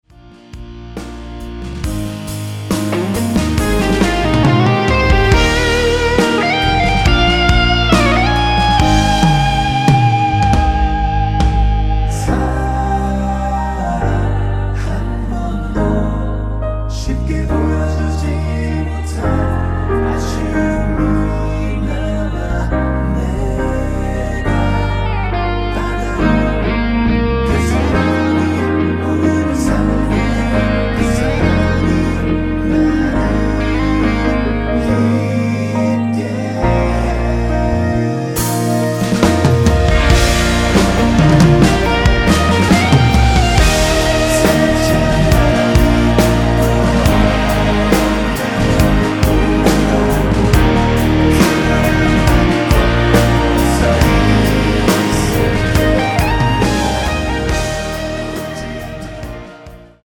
원키에서(-1)내린 코러스 포함된 MR입니다.(미리듣기 확인)
Eb
앞부분30초, 뒷부분30초씩 편집해서 올려 드리고 있습니다.
중간에 음이 끈어지고 다시 나오는 이유는